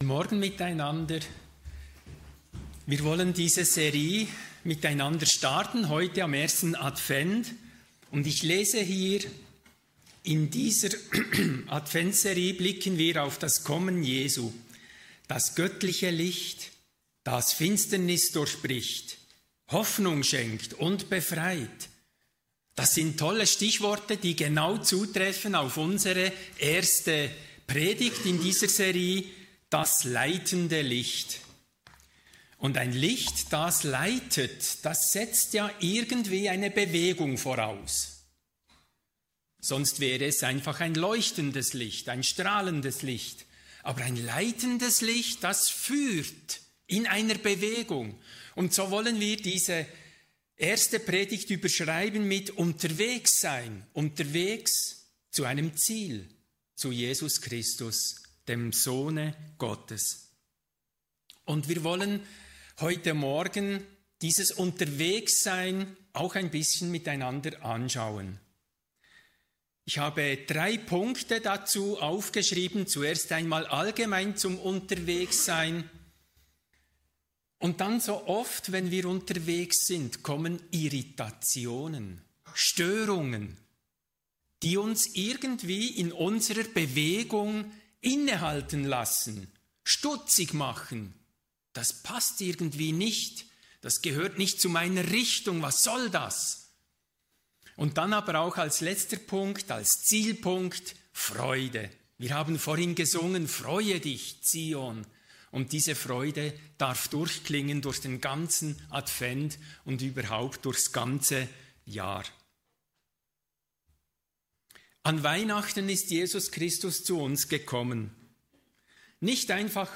Kategorie: Adventsgottesdienst